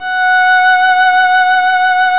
Index of /90_sSampleCDs/Roland L-CD702/VOL-1/STR_Violin 1-3vb/STR_Vln1 _ marc
STR VLN MT0K.wav